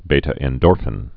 (bātə-ĕn-dôrfĭn, bē-)